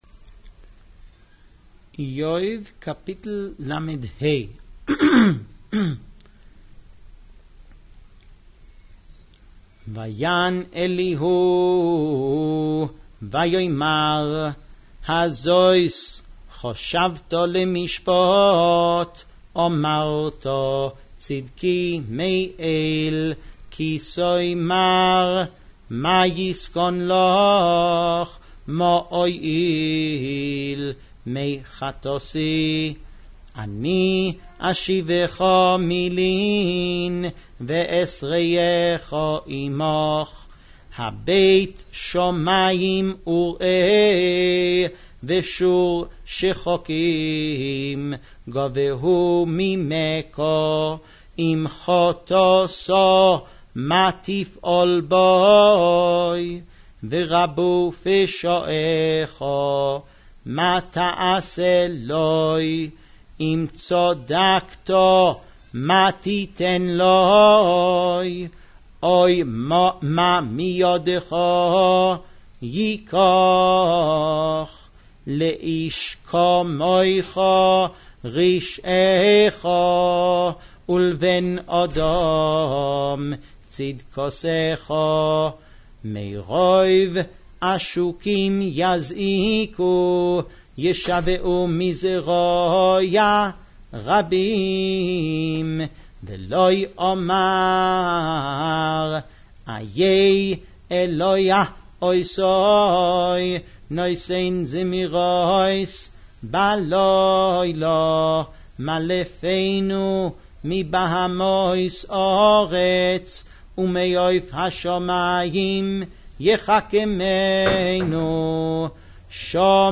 Index of /files/00 עברית/Leining Nach/Iyov Ashkenzi